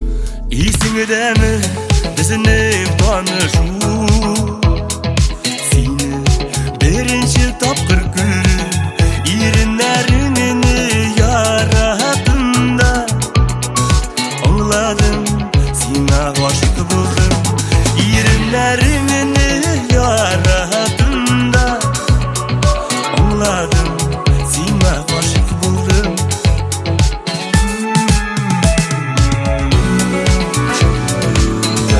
татарские , поп